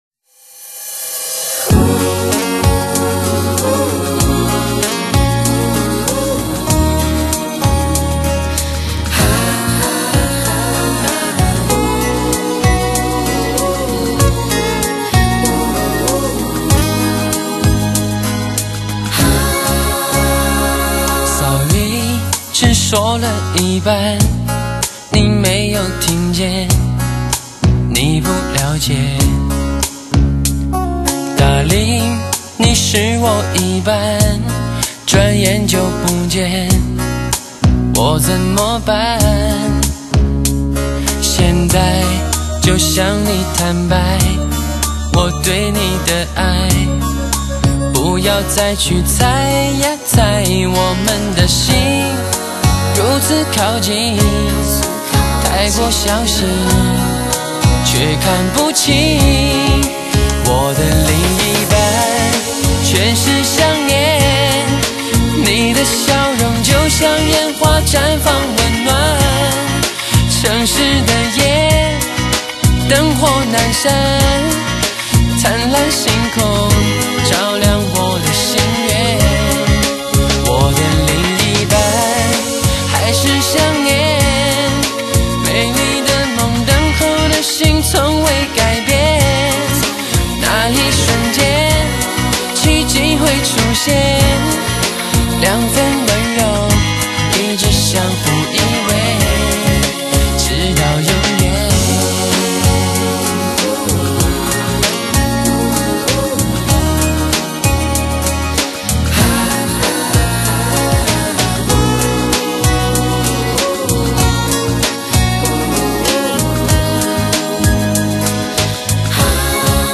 浪漫...凄美...温馨的情歌都很动心...动情...动人...